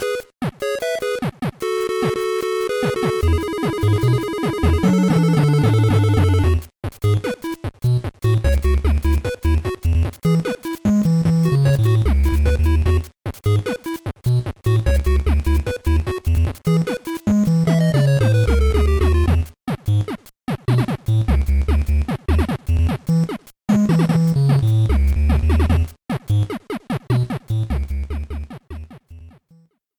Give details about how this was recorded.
Reduced to 30 seconds, with fadeout.